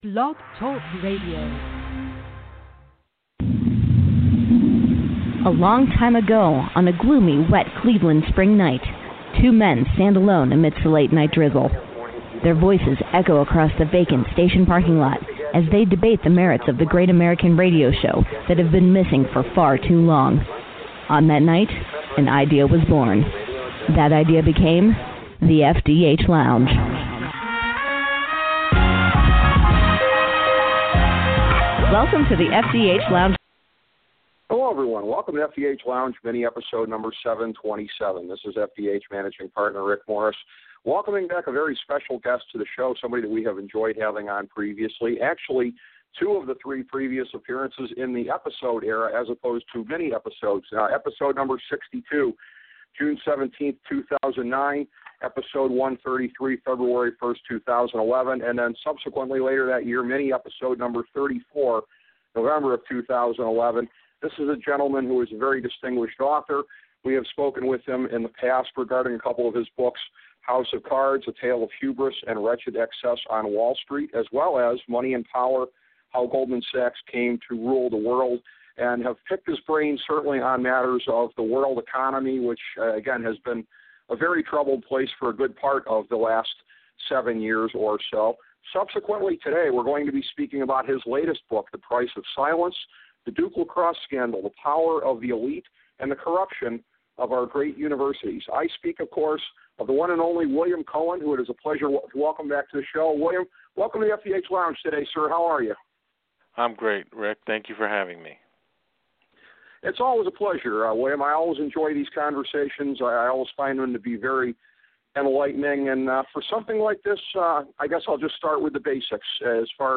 A conversation with William Cohan